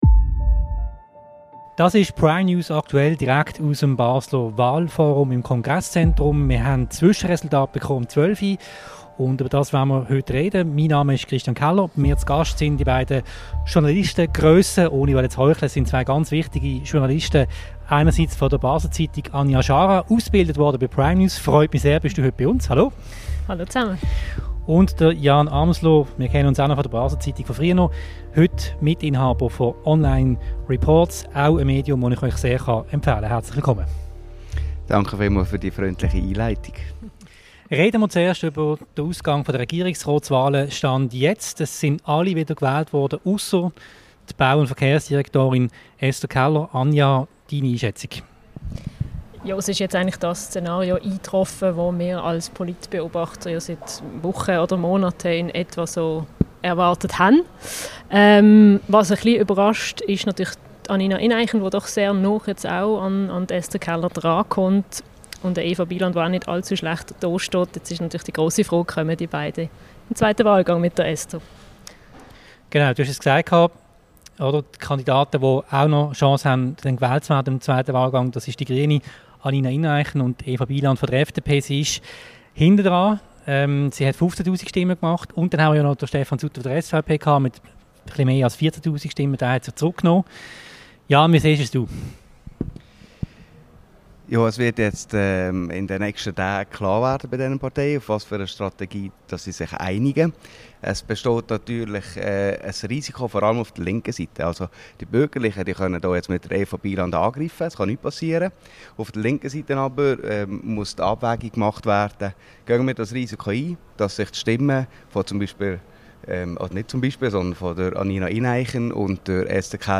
Einschätzungen live aus dem Wahlforum.